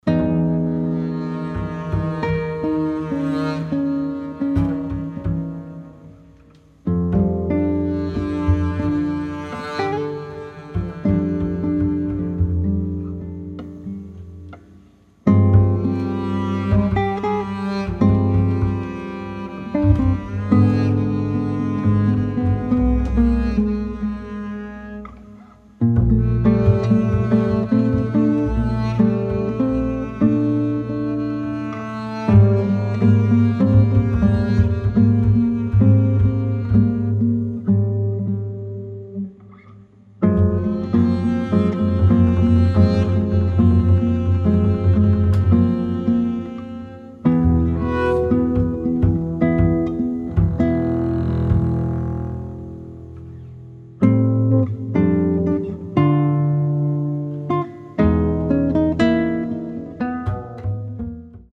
acoustic guitar
acoustic bass